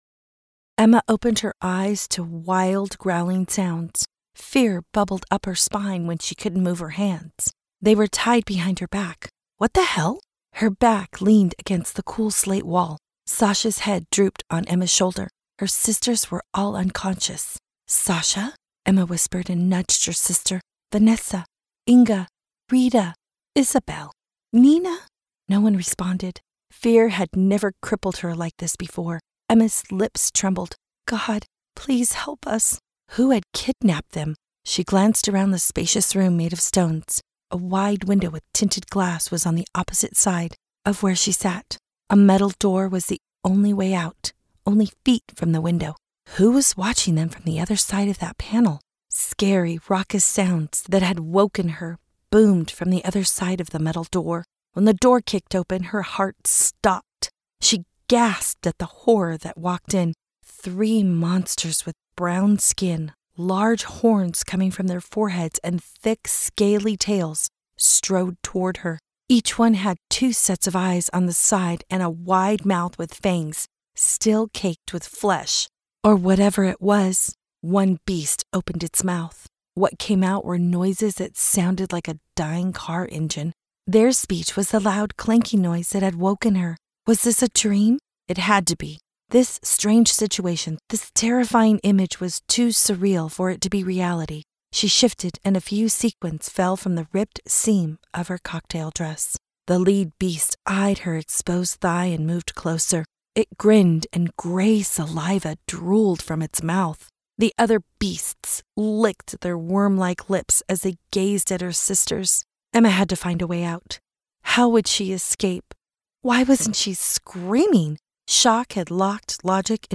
• Audiobook
An Alien Rescue 5 min retail sample.mp3